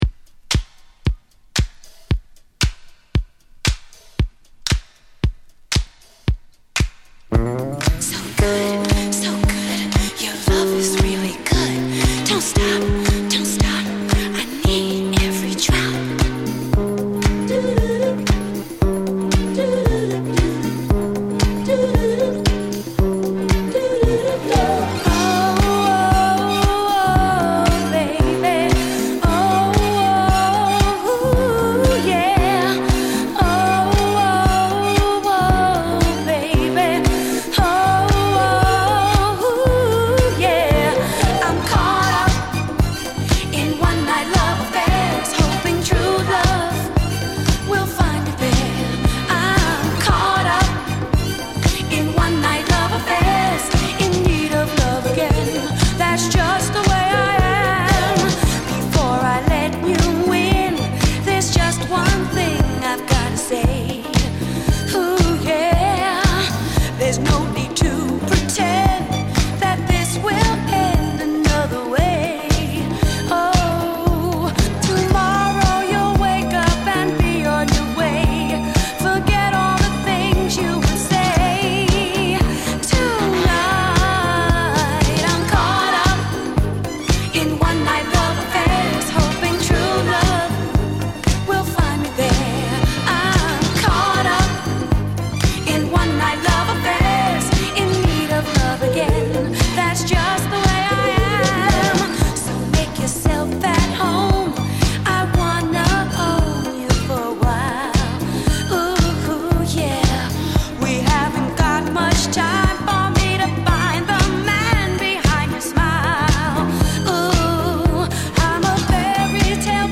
category Disco